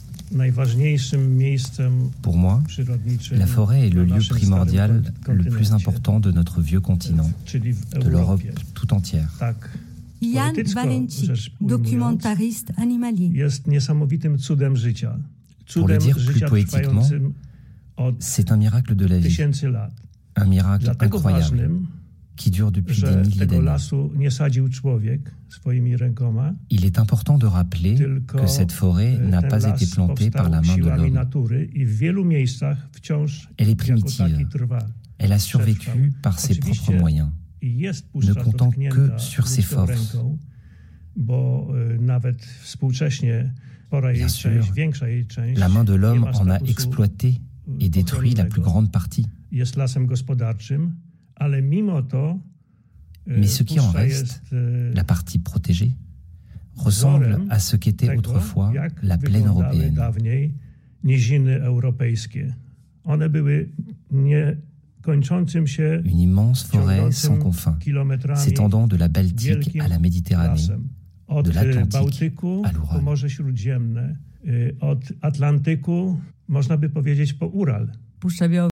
Voice Over France culture